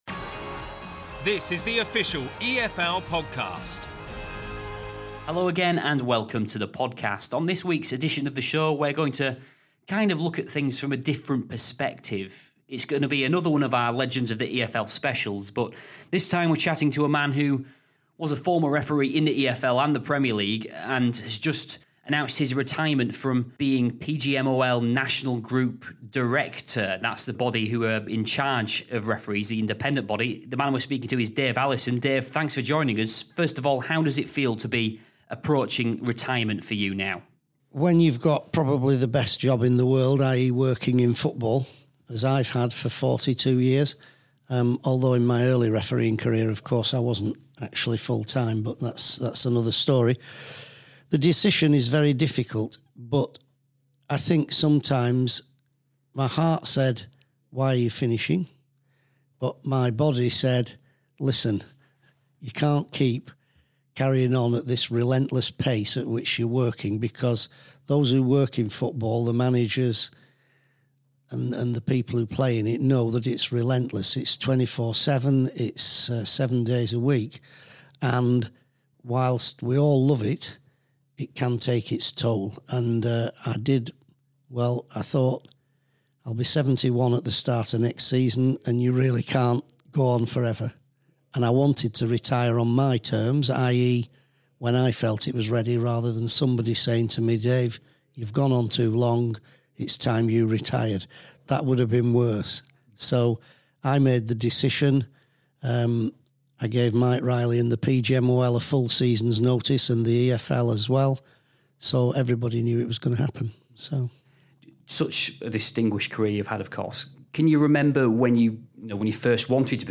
an in-depth chat